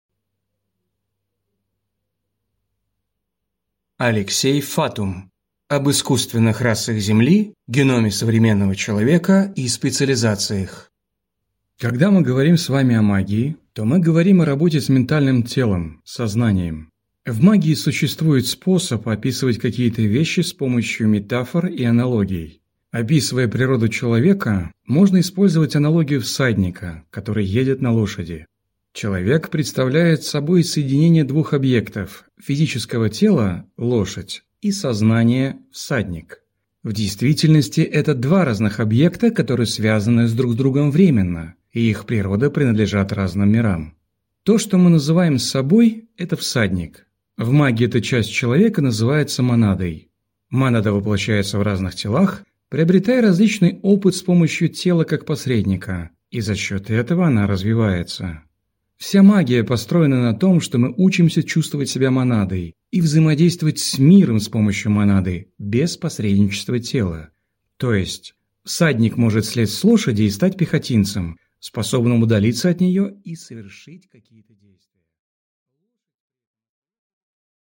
Аудиокнига Об искусственных расах Земли, геноме современного человека и специализациях | Библиотека аудиокниг